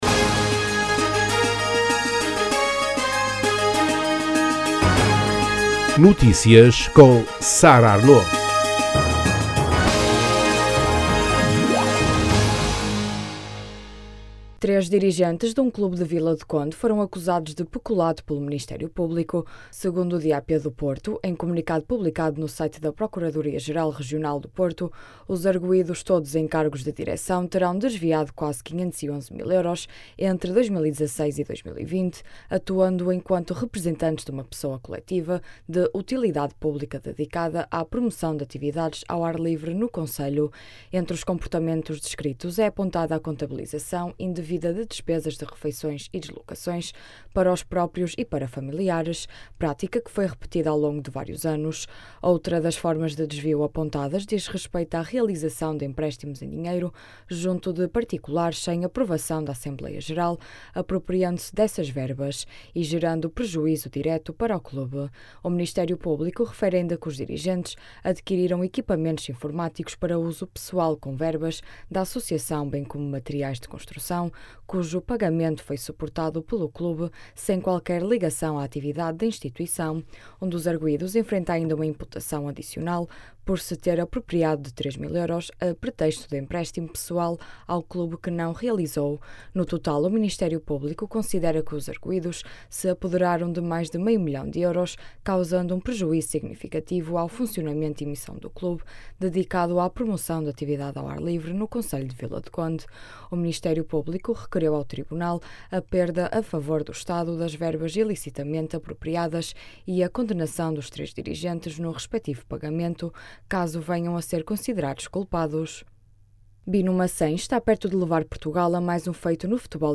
Paulo Vasques, vereador da cultura, detalha os trabalhos que estão neste momento a decorrer no monumento.
As declarações podem ser ouvidas na edição local.